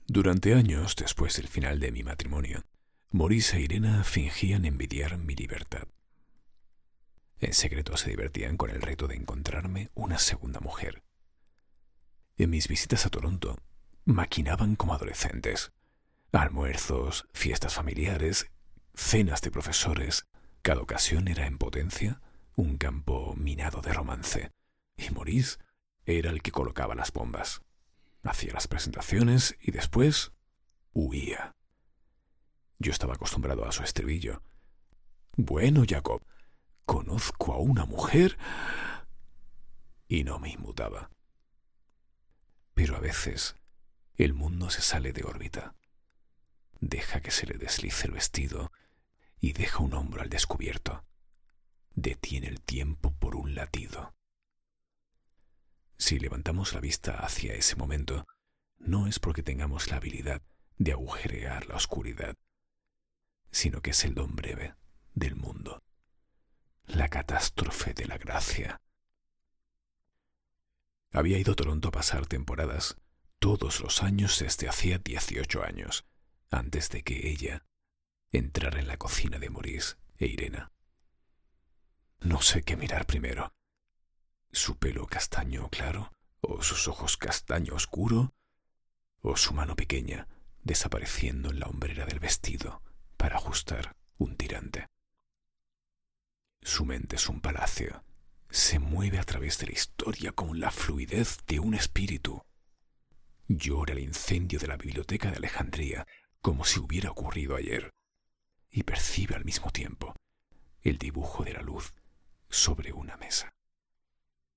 voz cálida, envolvente, elegante, transmite confianza, seguridad, seriedad con sentido de humor, versátil, seductora
Sprechprobe: Sonstiges (Muttersprache):
audiolibro solo voz.mp3